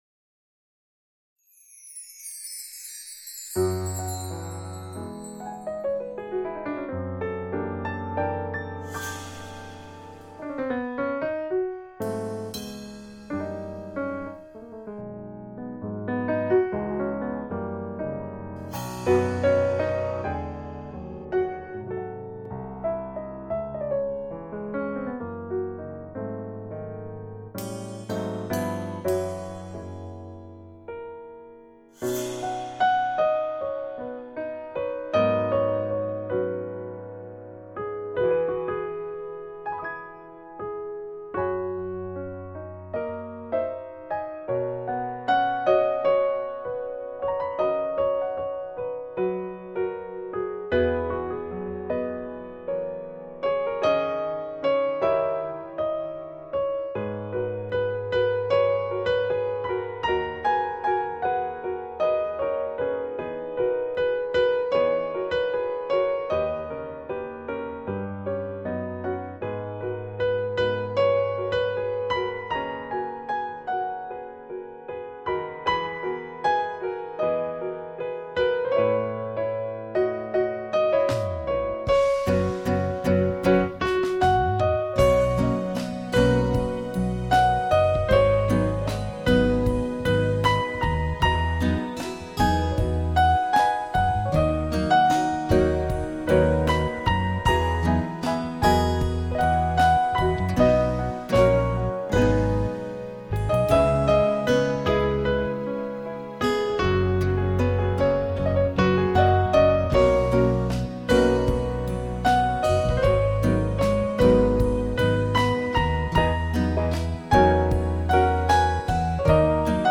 钢琴曲
★专辑的录音相当细腻讲究，钢琴的高音呈现出晶莹亮丽的质感